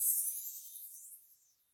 MB Open Hat (5).wav